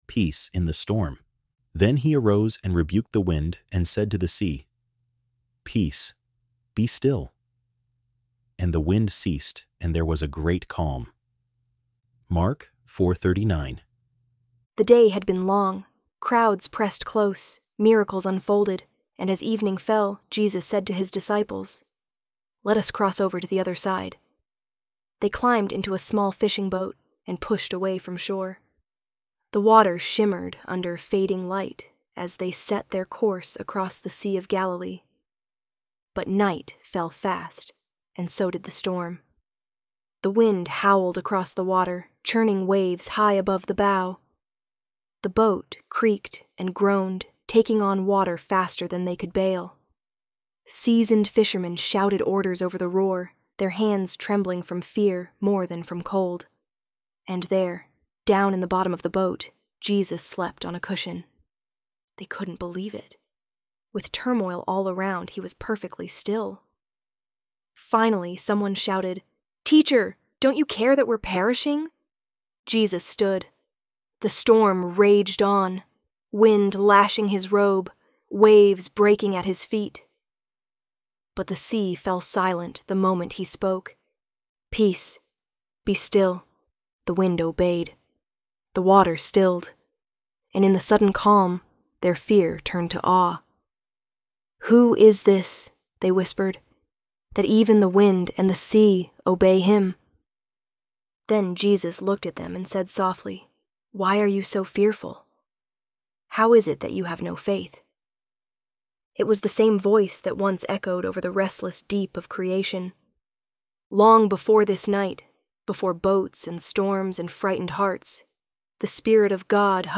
ElevenLabs_Peace_in_the_Storm.mp3